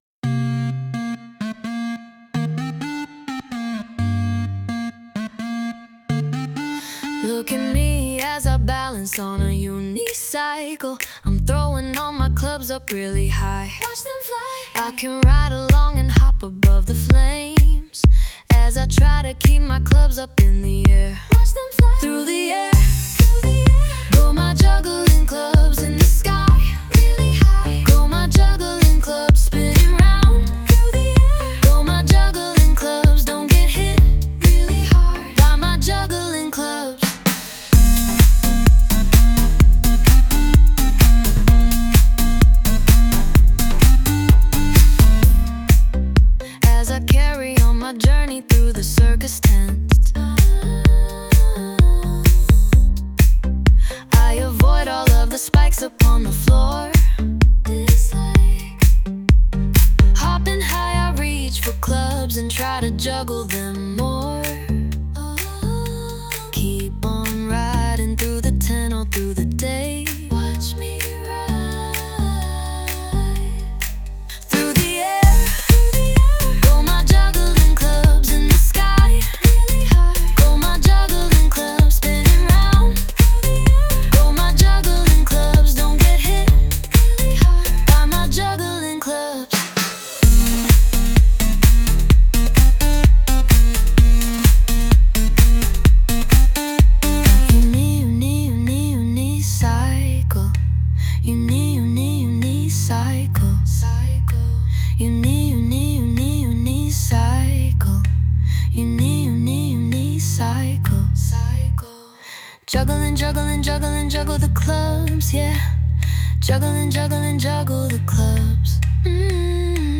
Sung by Suno